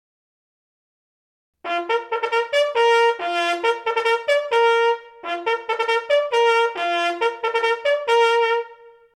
This is the rhythm of the bugle call used in the British Army to signal the beginning of the meal.
CookhouseDoor.mp3